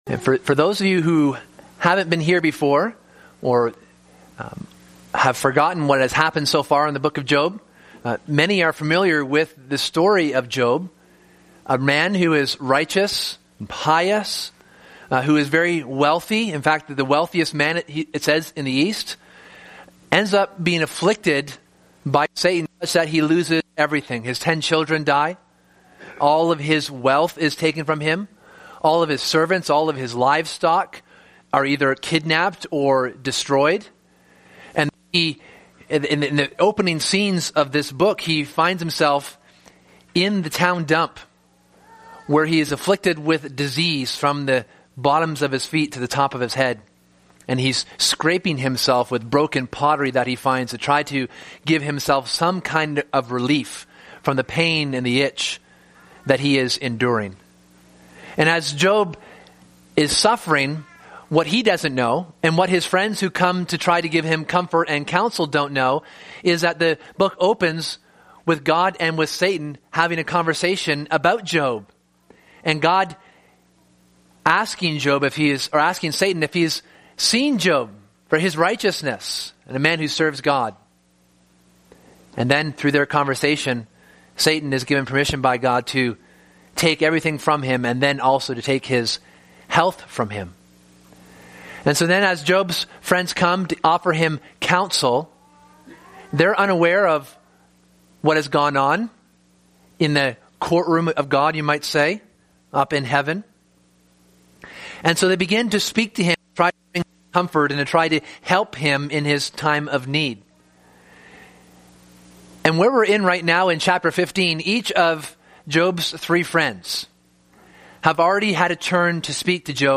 This book, and thus this sermon series, explores the nature and character of God.